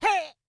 Cat Pain Sound Effect
Download a high-quality cat pain sound effect.
cat-pain-1.mp3